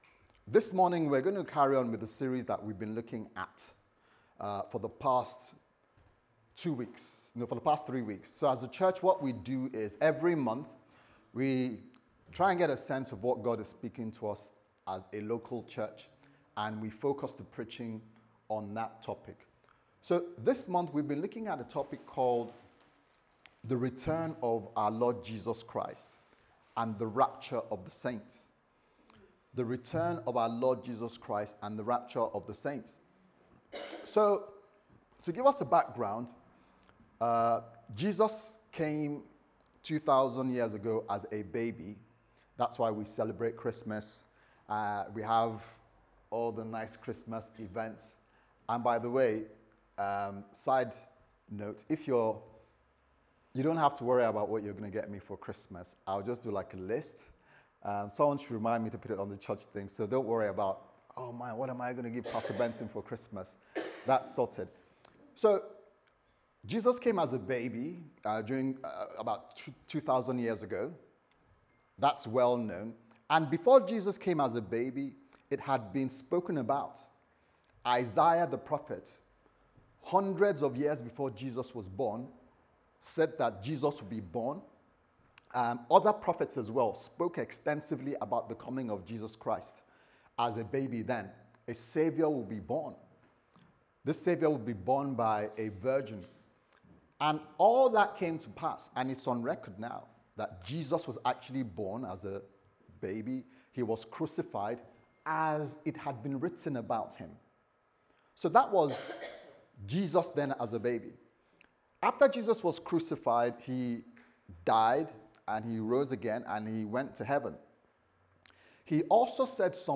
Service Type: Sunday Service Sermon